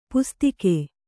♪ pustike